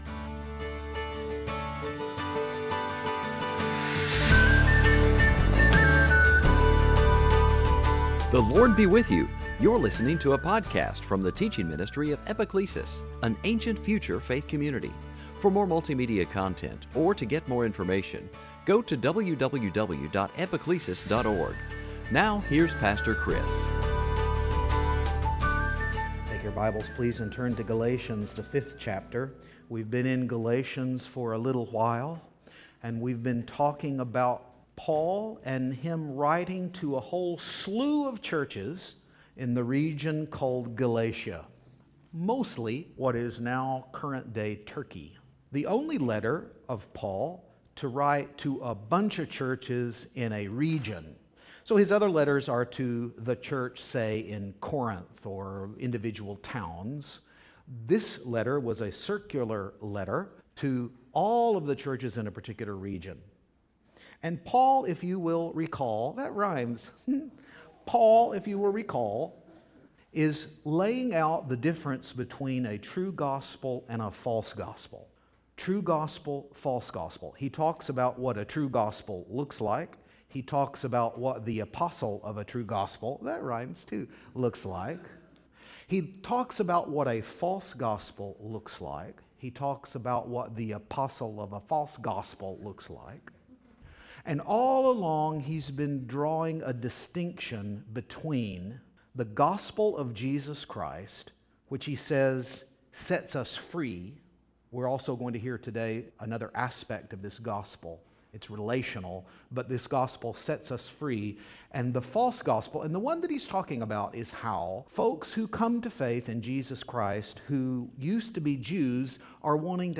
Listen in for a teaching on family, inheritance, and even a catchy little tune that will help you remember the Fruit of the Spirit.
2016 Sunday Teaching family Fruit of the Spirit Galatians inheritance Season after Pentecost